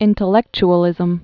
(ĭntl-ĕkch-ə-lĭzəm)